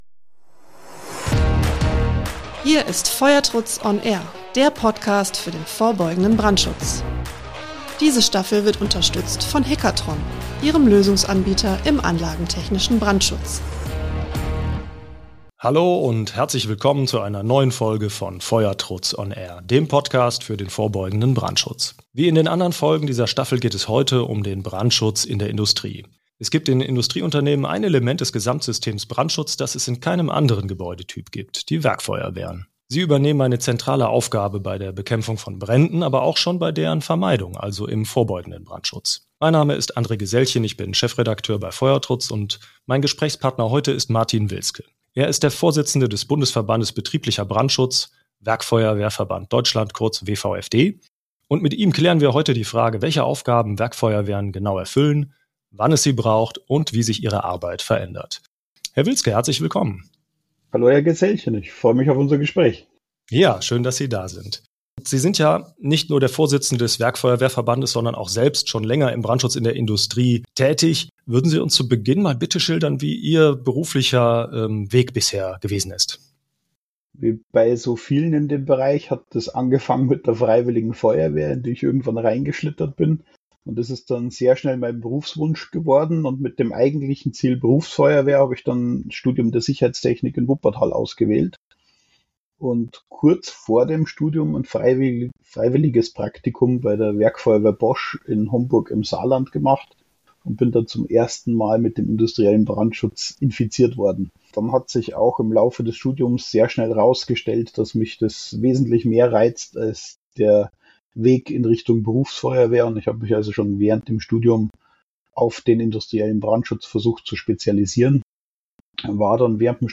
#3: Werkfeuerwehren in der Industrie | Im Gespräch